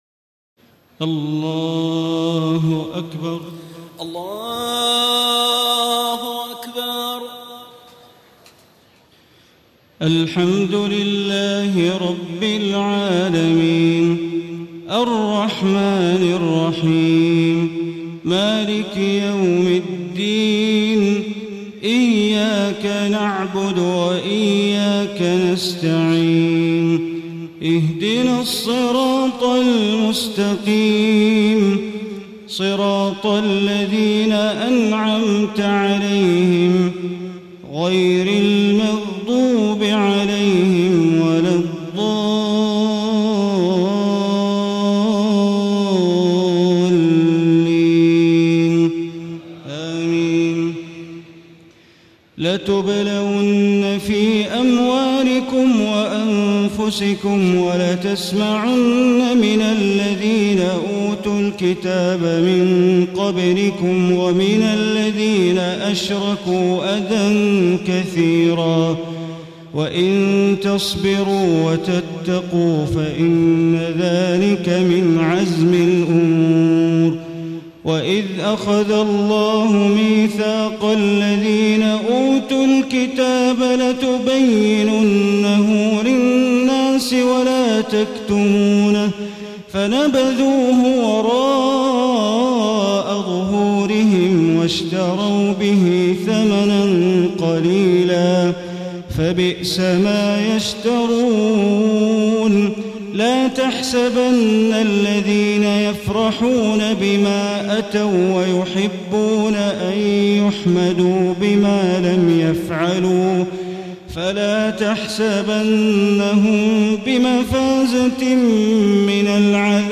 تهجد ليلة 24 رمضان 1435هـ من سورتي آل عمران (186-200) و النساء (1-24) Tahajjud 24 st night Ramadan 1435H from Surah Aal-i-Imraan and An-Nisaa > تراويح الحرم المكي عام 1435 🕋 > التراويح - تلاوات الحرمين